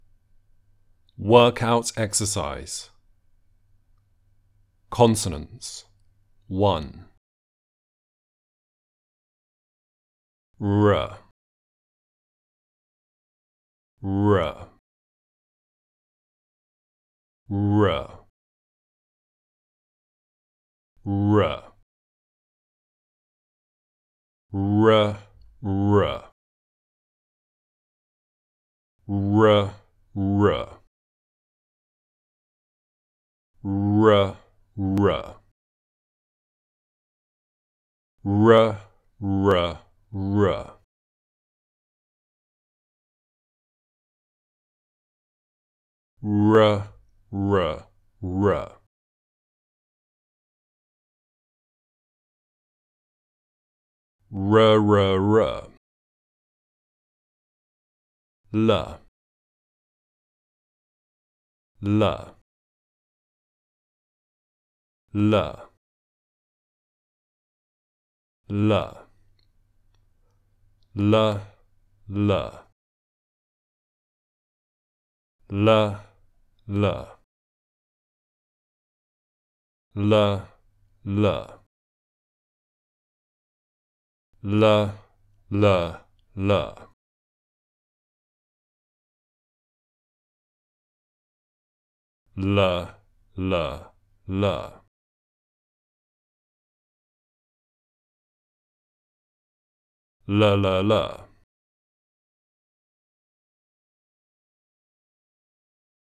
The Basic Building Blocks of Speech - Level 01 - British English Pronunciation RP Online Courses
Misc consonants 01
10_consonant_misc_01.mp3